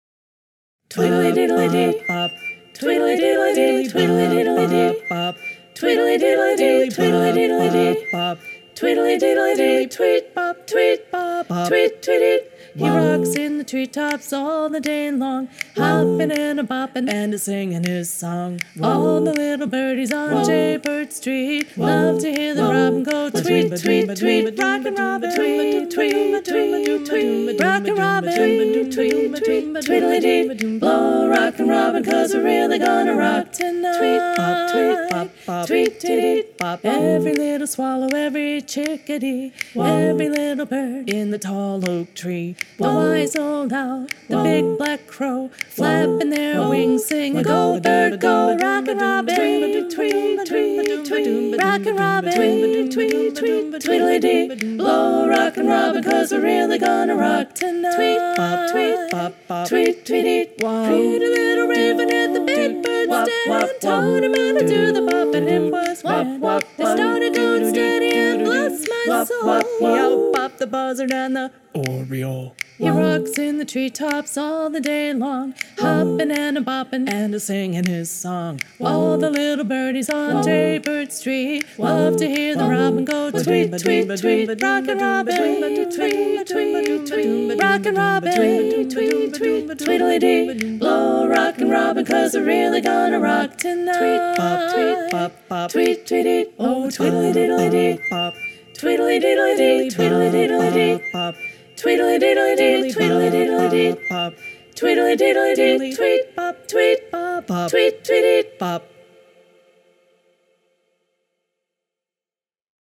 Master (Full Mix)